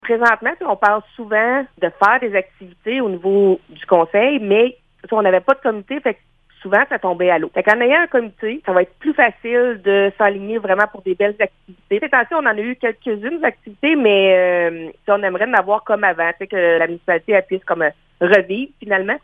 L’objectif du conseil municipal est de revitaliser la vie culturelle de Montcerf-Lytton comme cela a été le cas auparavant. C’est ce qu’explique la mairesse, Véronique Danis :